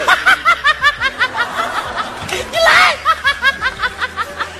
SFX宋小宝哈哈哈哈哈音效下载
SFX音效